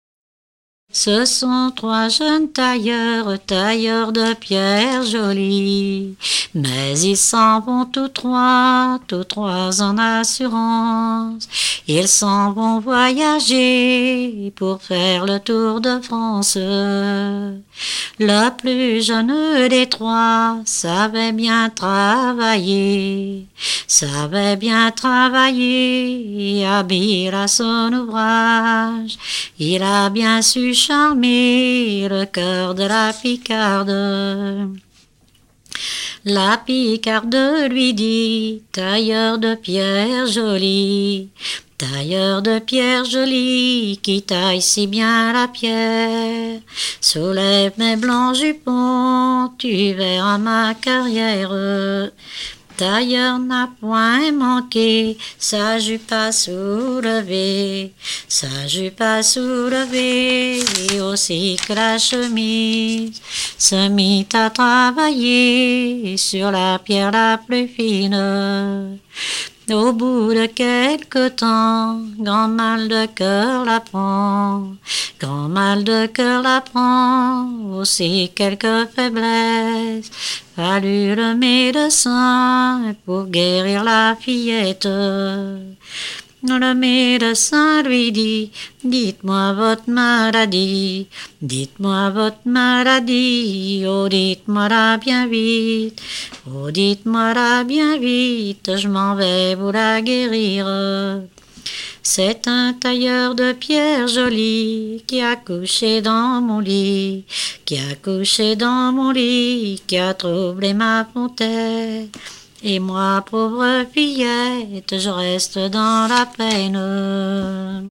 Dompierre-sur-Yon
Genre strophique